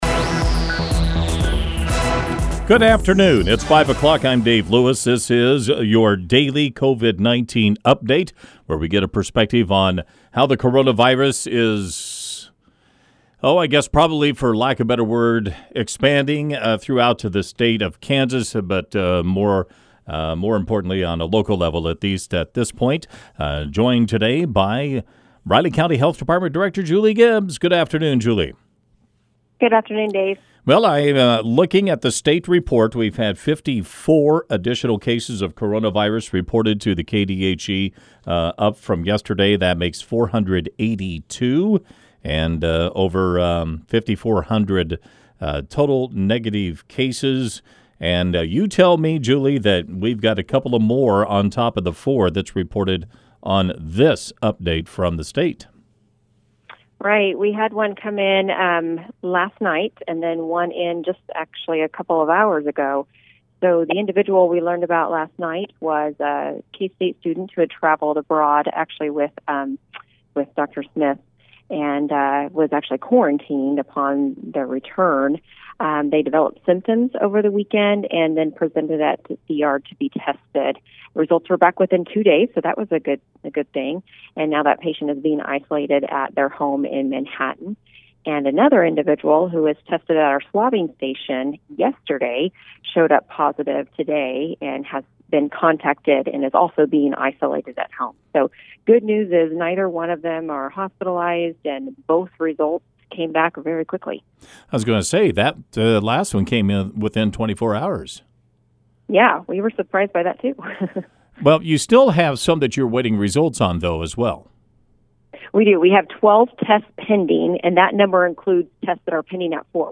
4/1/20 - COVID-19 5 p.m. update with RCHD Director Julie Gibbs - News Radio KMAN